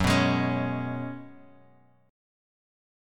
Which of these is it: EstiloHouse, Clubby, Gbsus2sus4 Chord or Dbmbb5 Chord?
Gbsus2sus4 Chord